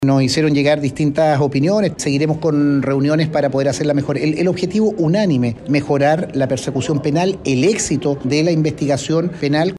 El senador socialista, Alfonso de Urresti, dice que ante todo la idea del proyecto es mejorar la persecución penal, para el mejor éxito de investigaciones secretas o reservadas.